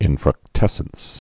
(ĭnfrŭk-tĕsəns)